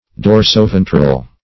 Search Result for " dorsoventral" : Wordnet 3.0 ADJECTIVE (1) 1. extending from the back to the belly ; The Collaborative International Dictionary of English v.0.48: Dorsoventral \Dor`so*ven"tral\, a. [dorsum + ventral.]
dorsoventral.mp3